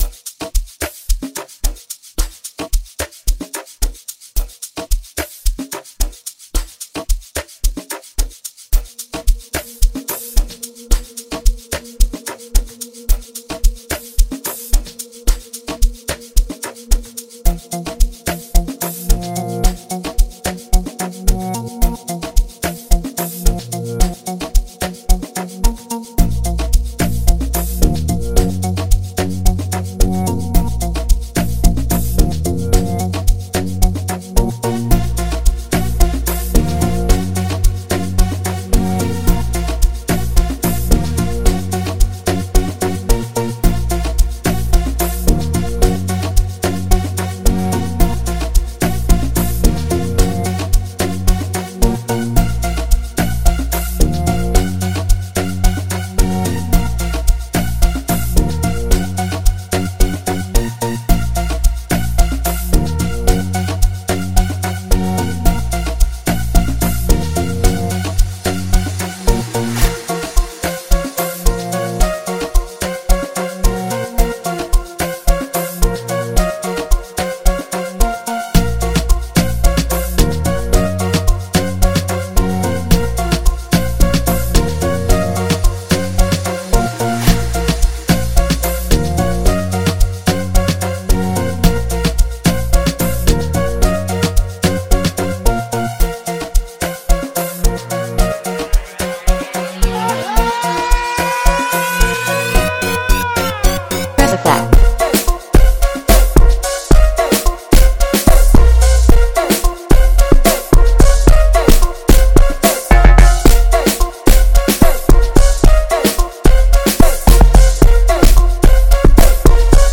feel-good energy
unique vocal style and laid-back delivery